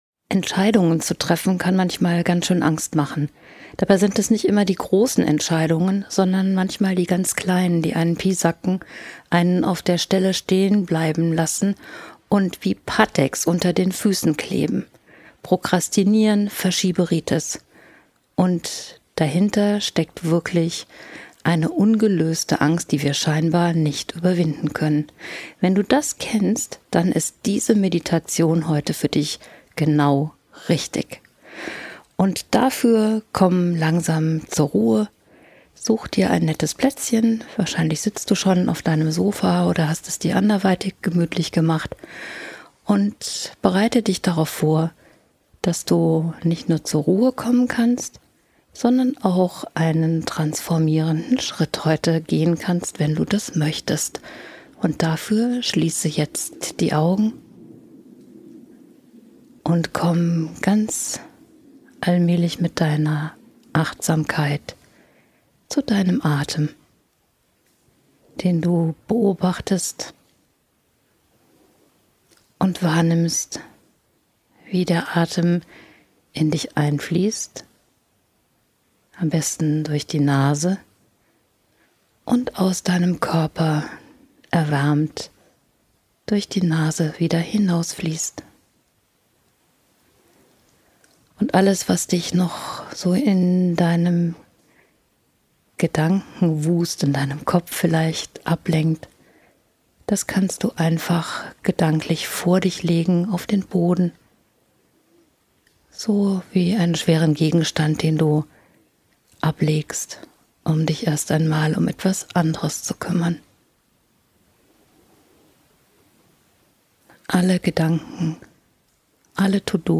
Wenn du rauswillst aus der Angst, um wieder in Bewegung zu kommen, um endlich eine bestimmte Entscheidung zu treffen und in die Tat umzusetzen, dann ist das heute genau die richtige Meditation für dich.